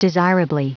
Prononciation du mot desirably en anglais (fichier audio)
Prononciation du mot : desirably